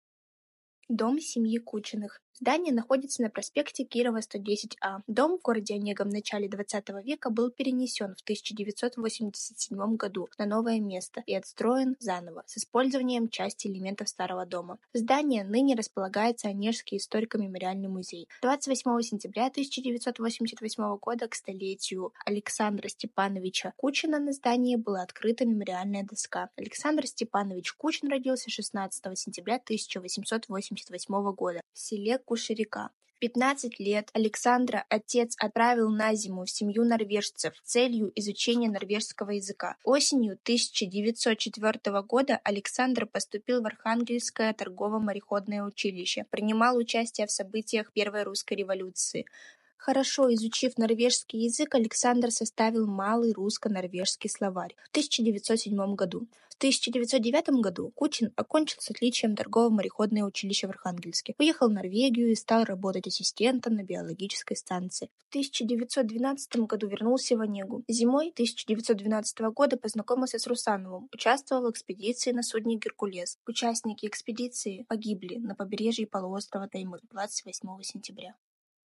Аудиогид: